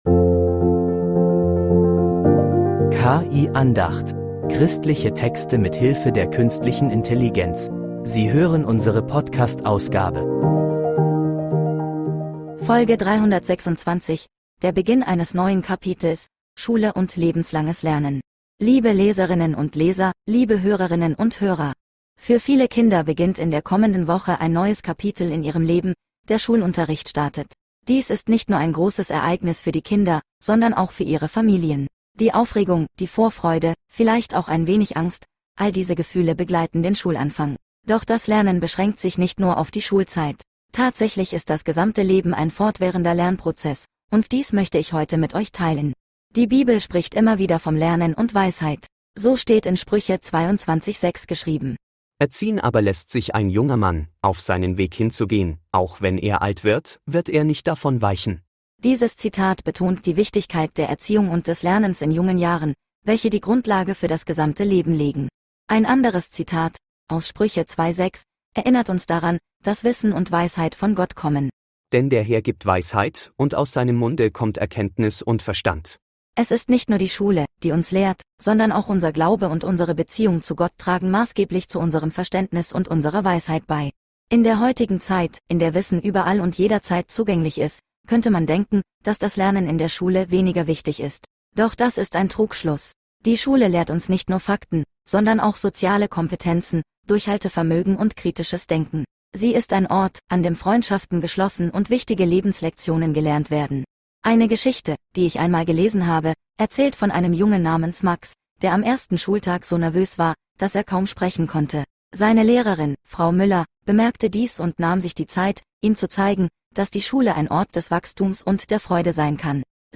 KI-Andacht